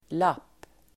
Uttal: [lap:]